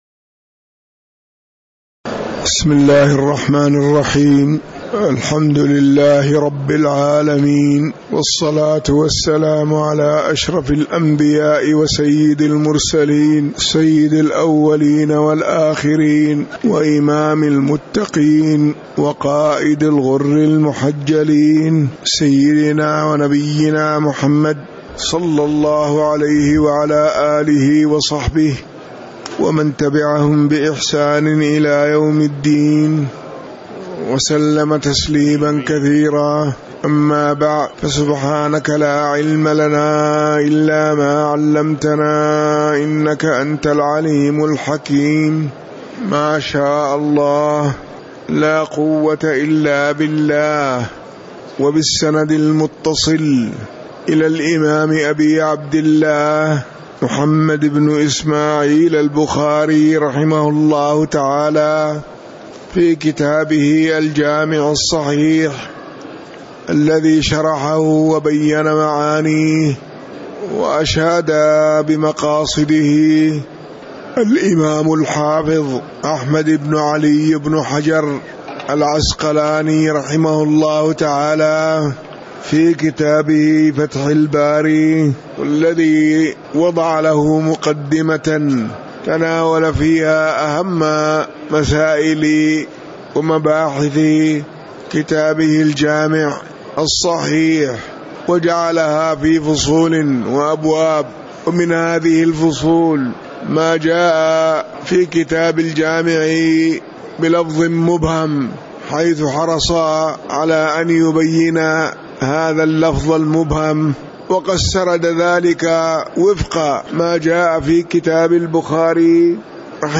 تاريخ النشر ١٠ رجب ١٤٤٠ هـ المكان: المسجد النبوي الشيخ